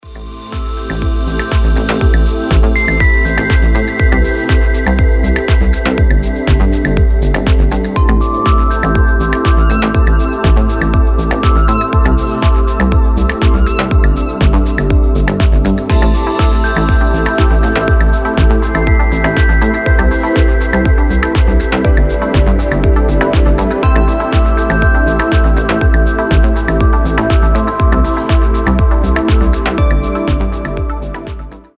мелодичные
Electronic
без слов
progressive house
Вдохновляющая мелодия без слов на будильник или звонок